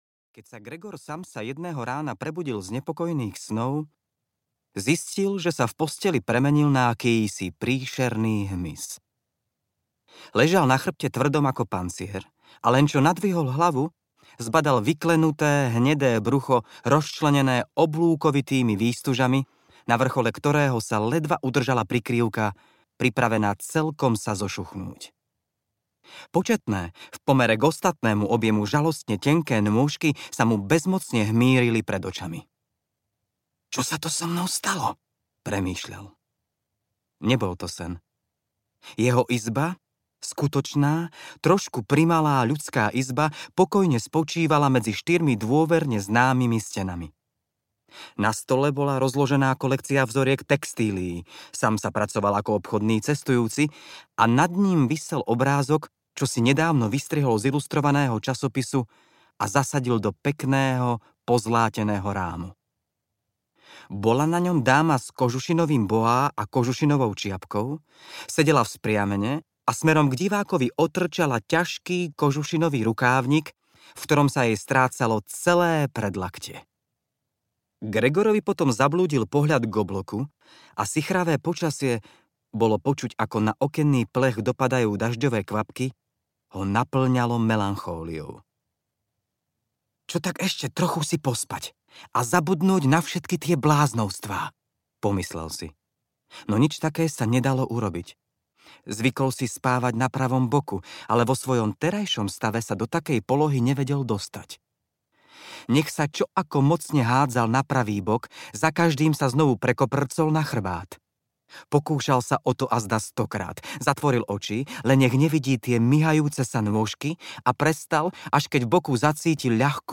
Premena audiokniha
Ukázka z knihy